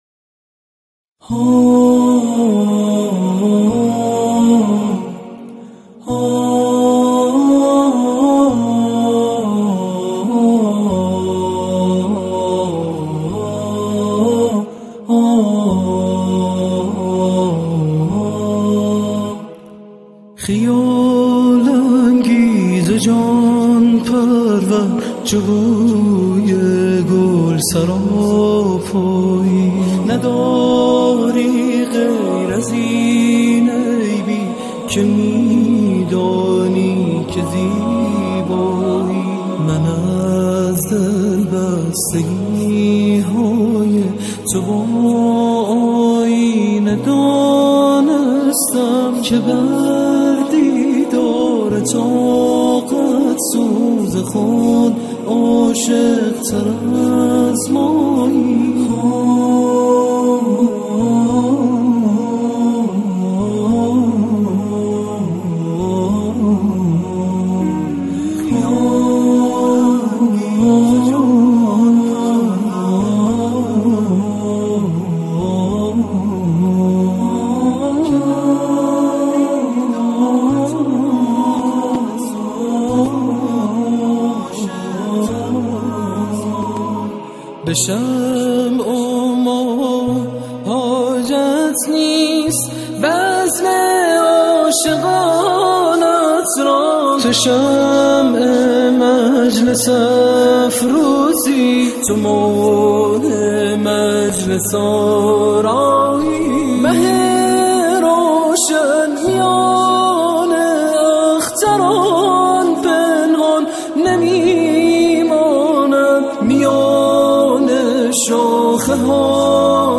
آکاپلا
گروه کر